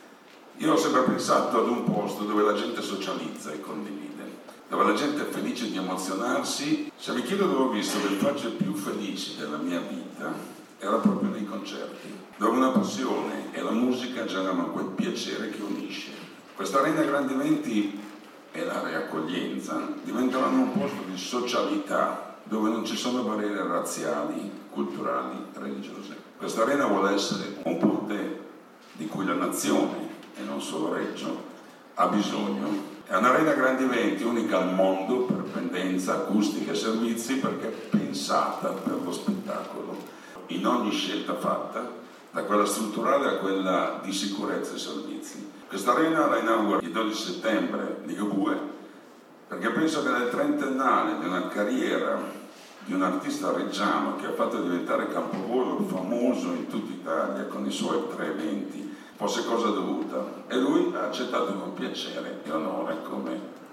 Al microfono